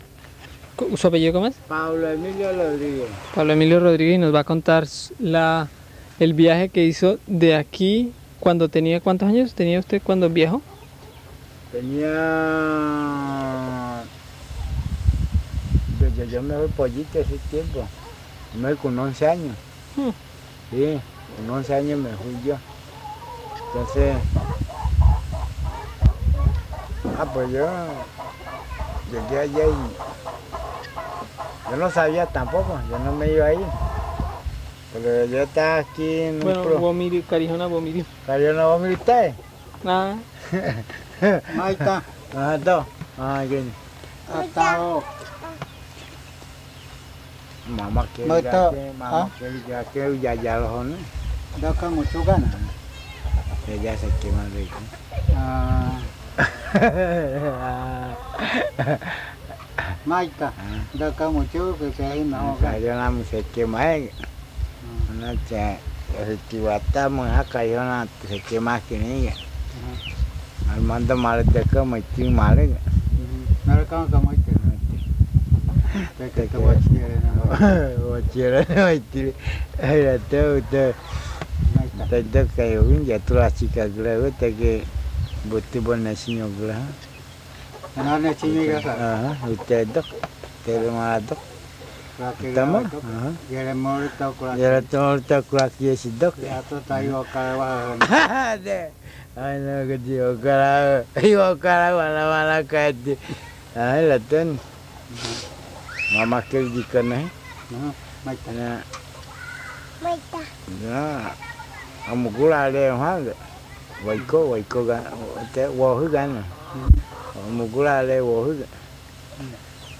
This recording is part of the collection of mythis, stories and songs collected by the Karijona Linguistics Seedbed (Department of Linguistics, Faculty of Human Sciences, Bogotá campus of UNAL) collected from the Karijona people in the Caquetá and Vaupés Rivers between 1985 and 2021 .